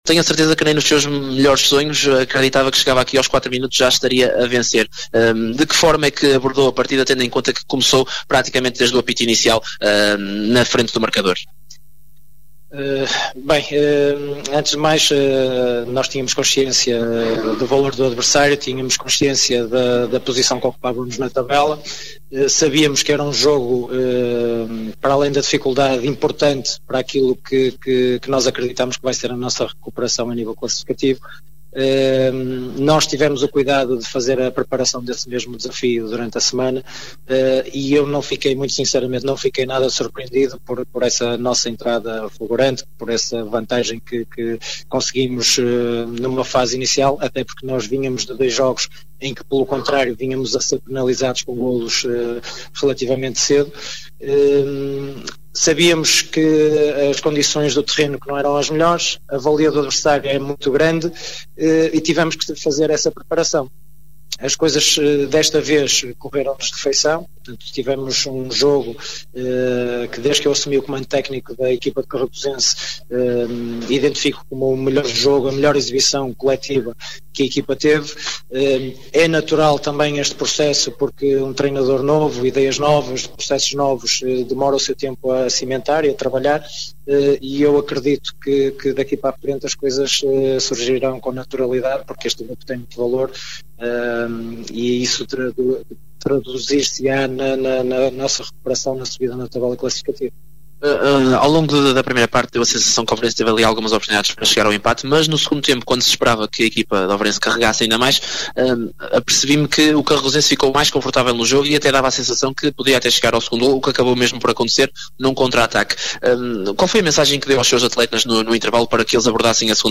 • Declarações JD Carregosense |